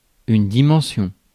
Ääntäminen
Synonyymit taille mesure Ääntäminen France: IPA: [di.mɑ̃.sjɔ̃] Haettu sana löytyi näillä lähdekielillä: ranska Käännös Substantiivit 1. ulottuvuus 2. koko Muut/tuntemattomat 3. dimensio Suku: f .